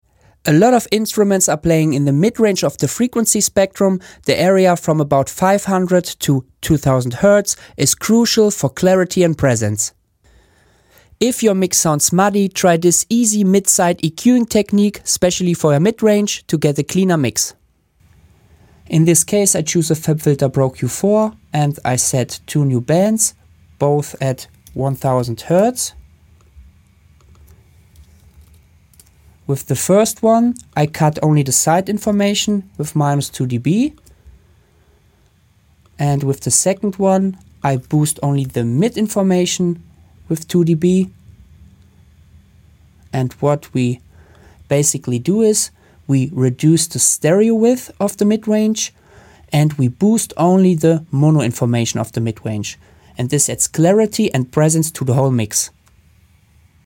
A lot of Instruments are playing in the mid-range of the frequency spectrum.